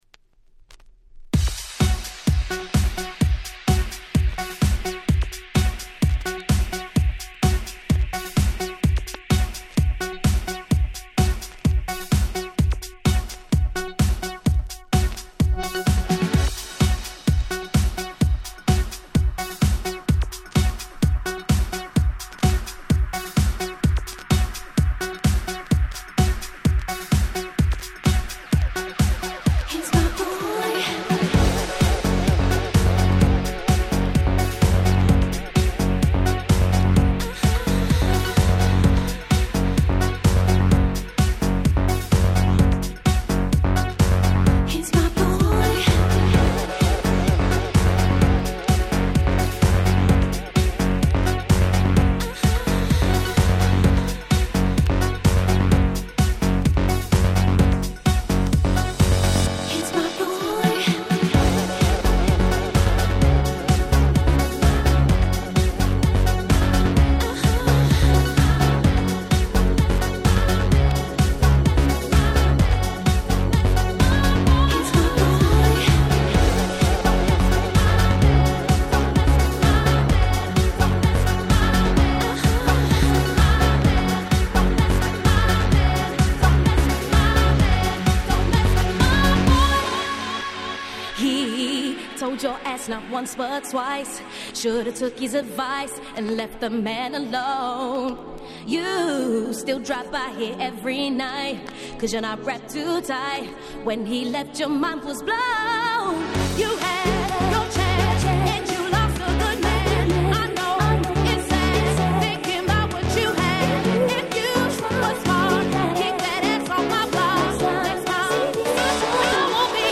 07' Nice Vocal House !!
超キャッチー！！